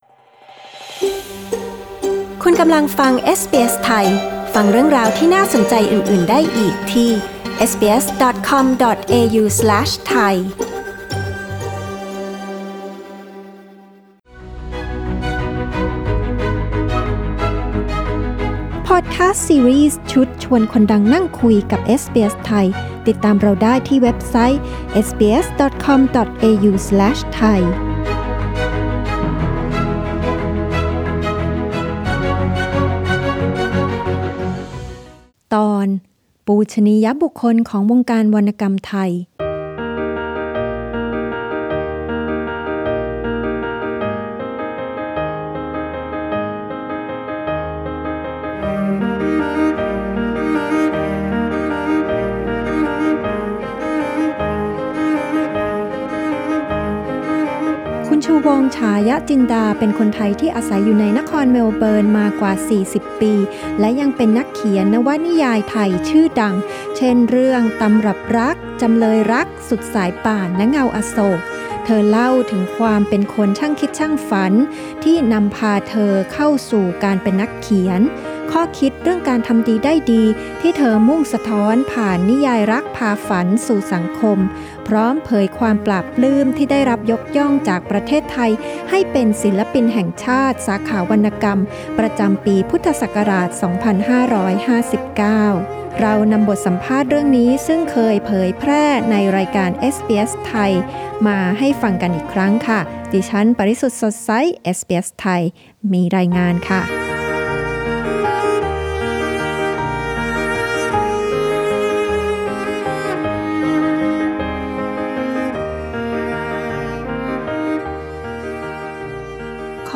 บทสัมภาษณ์นี้เผยแพร่ในรายการเอสบีเอส ไทย ครั้งแรกเมื่อ 31 ม.ค. 2017
ชูวงศ์ ฉายะจินดา ศิลปินแห่งชาติด้านวรรณศิลป์ของไทย จากเมลเบิร์น Source: SBS Thai/Pixabay